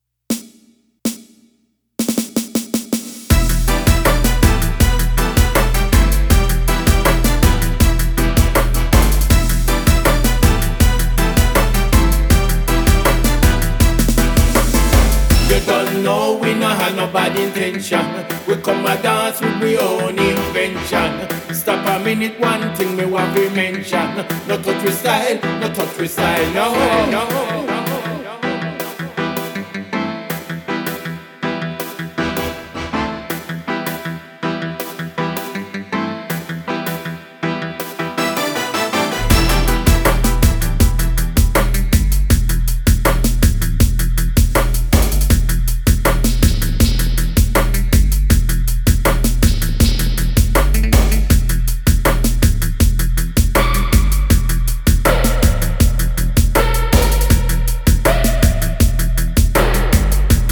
bringing us an upbeat riddim, mellow and heavy!